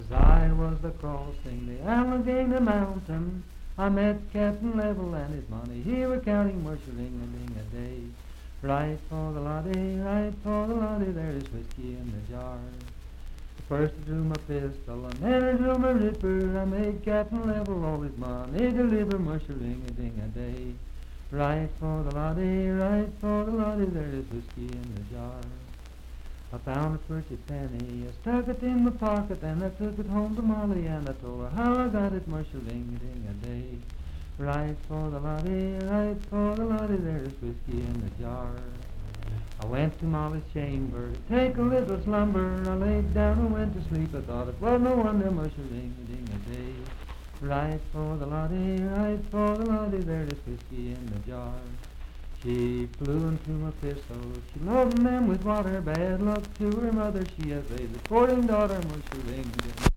Unaccompanied vocal music
Verse-refrain 5(8w/R).
Voice (sung)